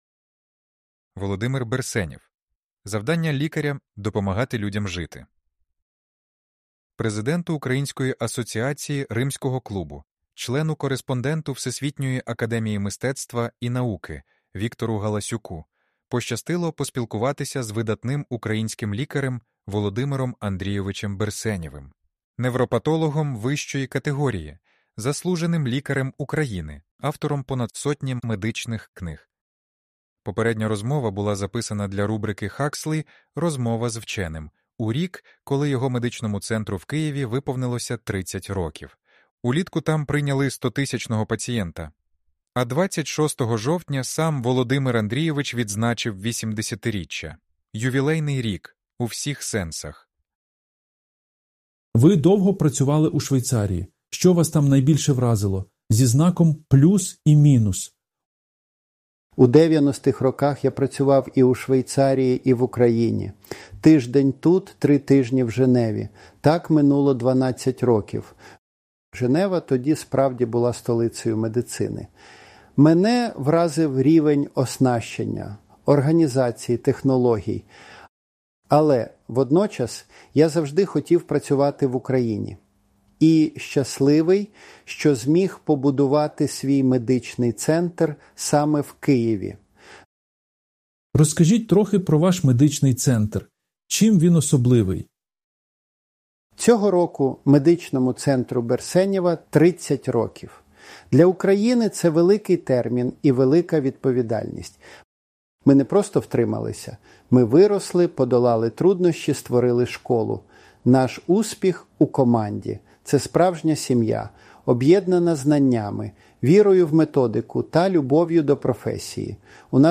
Аудіозапис інтерв’ю